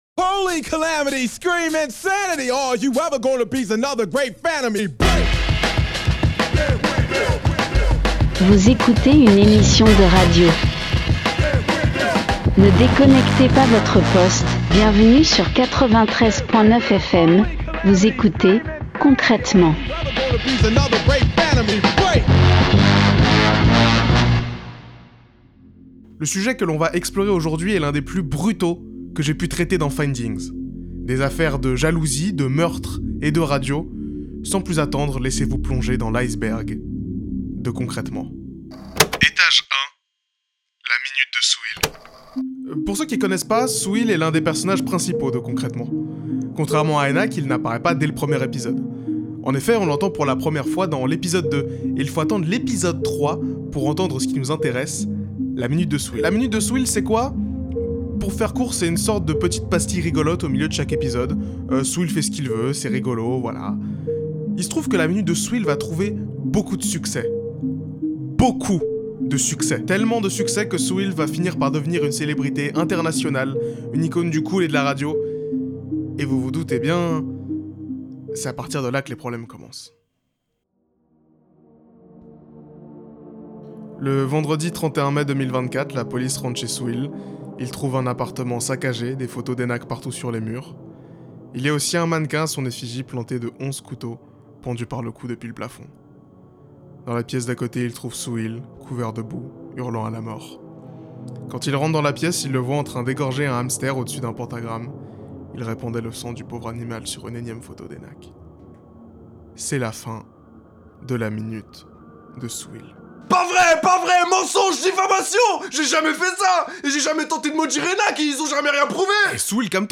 Partager Type Création sonore Société vendredi 21 juin 2024 Lire Pause Télécharger YOOOOOOOO C'EST L'HEURE DE LA PEUR !